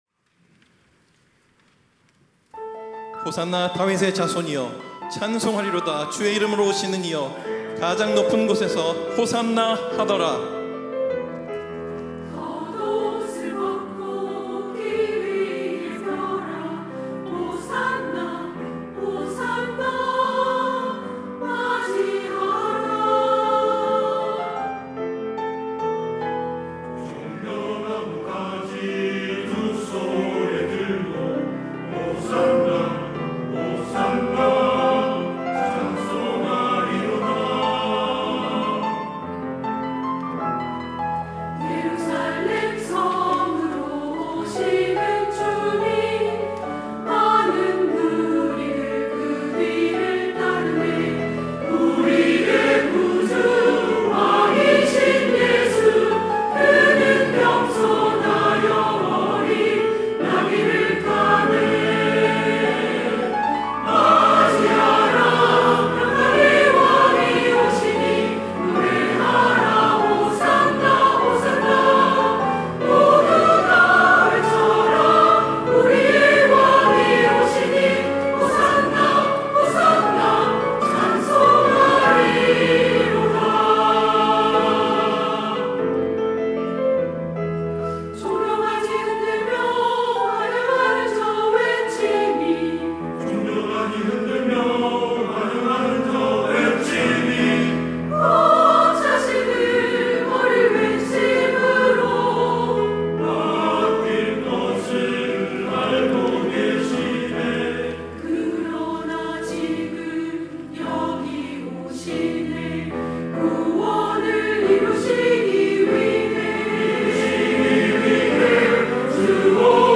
시온(주일1부) - 맞이하라
찬양대